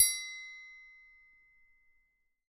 描述：硬开三角音
Tag: 命中 打击乐器 idiophone